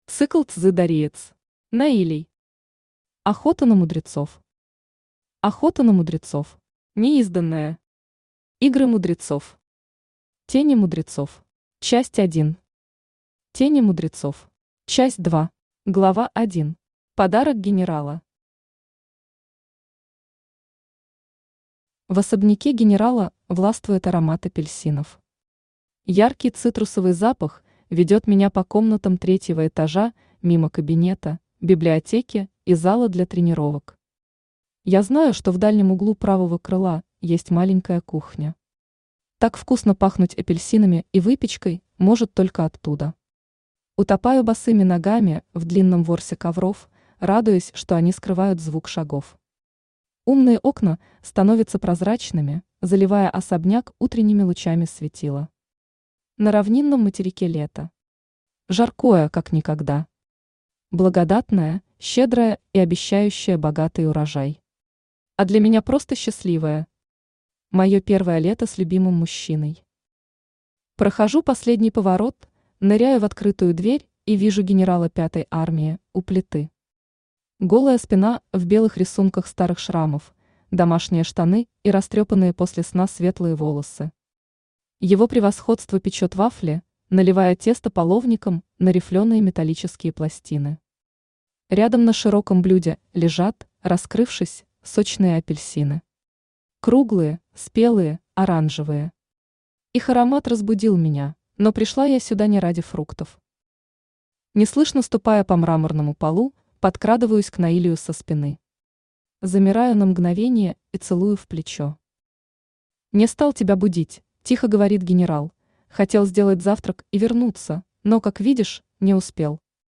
Аудиокнига Игры мудрецов | Библиотека аудиокниг
Aудиокнига Игры мудрецов Автор Дэлия Мор Читает аудиокнигу Авточтец ЛитРес.